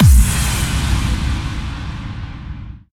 VEC3 FX Reverbkicks 26.wav